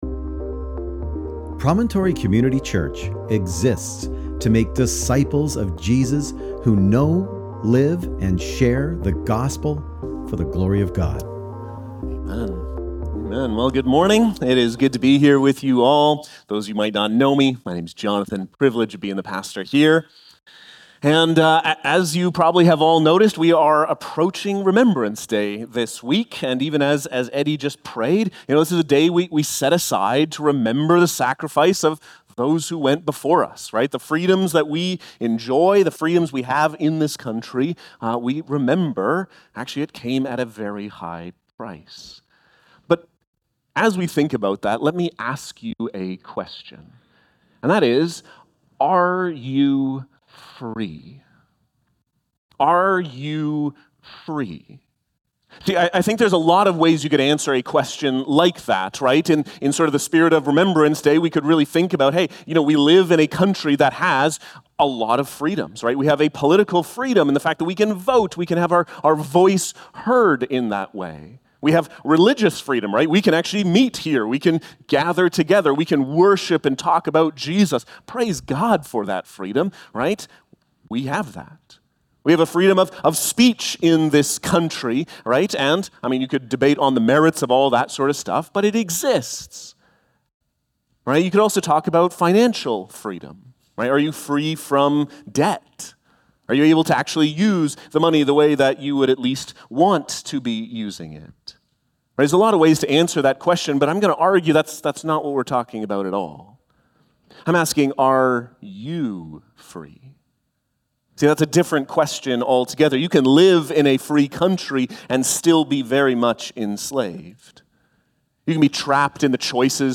Sermon Text: Galatians 5:1-15